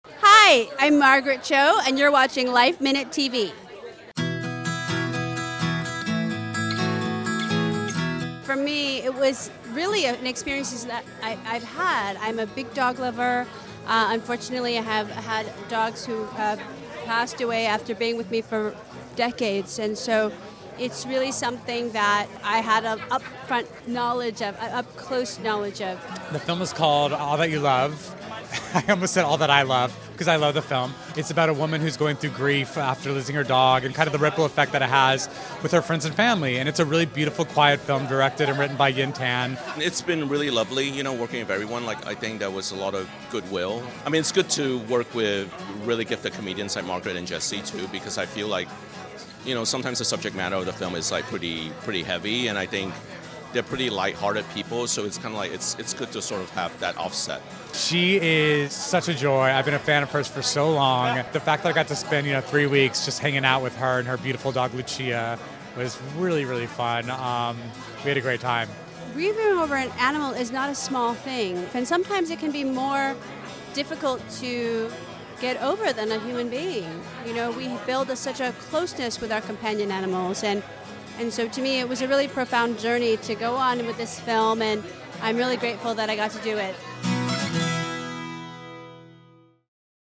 Margaret Cho and Jesse Tyler Ferguson on Dealing with Loss of a Pet at Tribeca Premiere of All That We Love